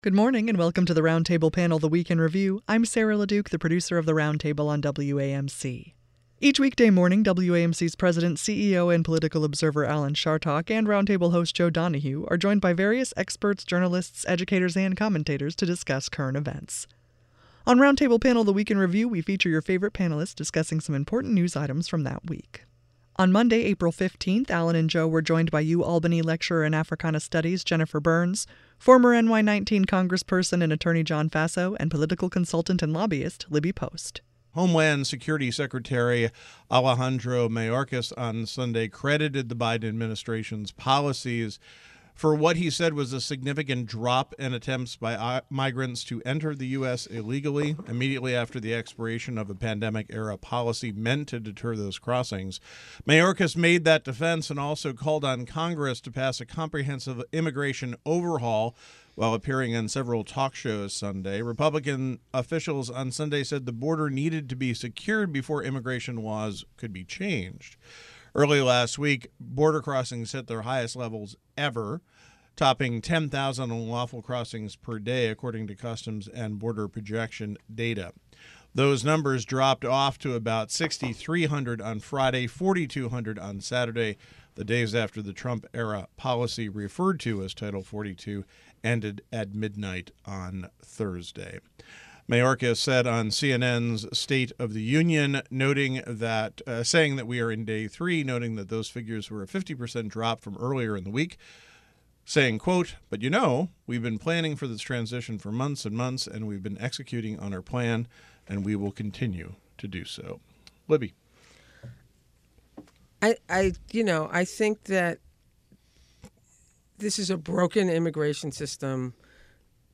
On Roundtable Panel: The Week in Review, we feature your favorite panelists discussing news items from the previous week.